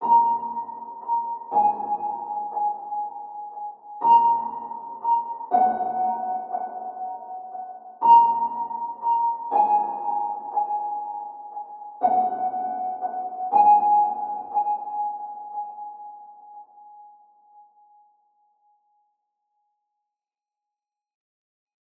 AV_Peacemaker_HighPiano_60bpm_Bbmin
AV_Peacemaker_HighPiano_60bpm_Bbmin.wav